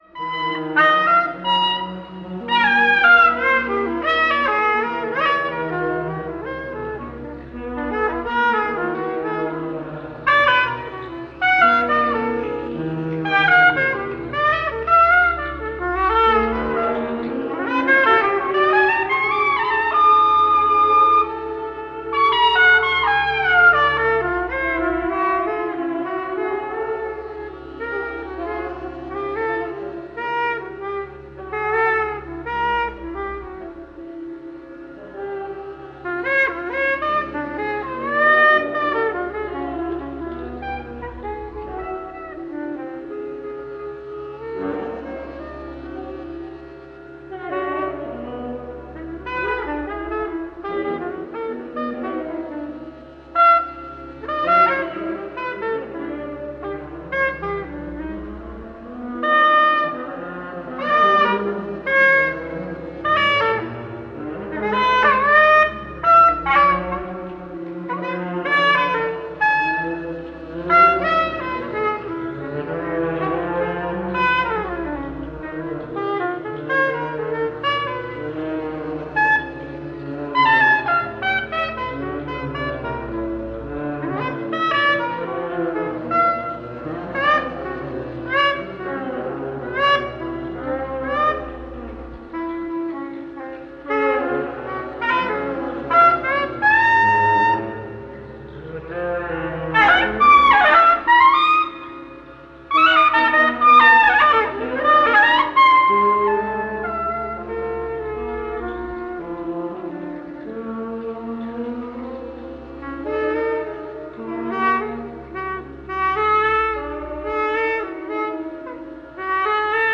Genre:ElectronicJazz
Style:AmbientAvantgardeExperimentalFree Improvisation